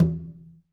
Conga-HitN_v2_rr2_Sum.wav